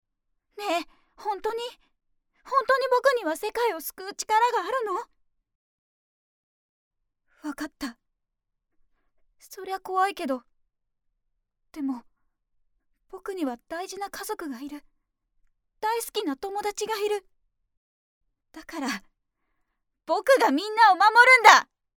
With a gentle and quiet voice, I can deliver any content in a friendly manner.
– Narration –
Gentle boy